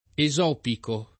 [ e @0 piko ]